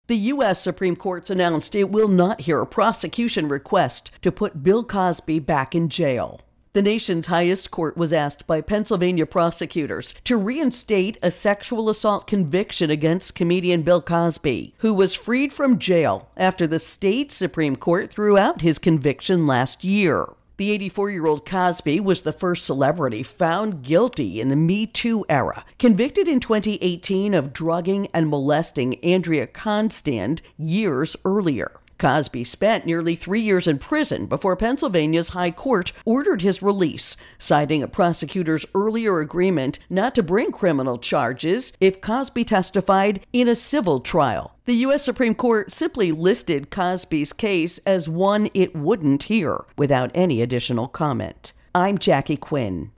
Supreme Court Bill Cosby Intro and Voicer